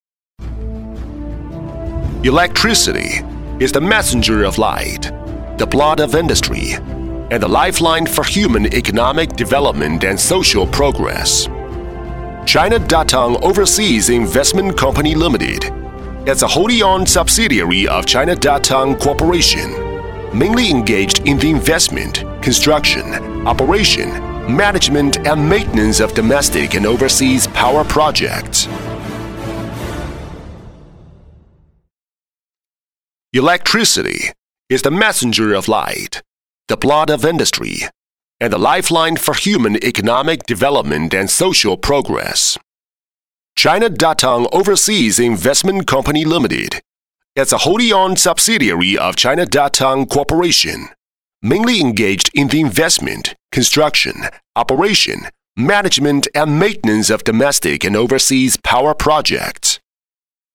中国大唐集团海外投资有限公司(激昂震撼宣传片)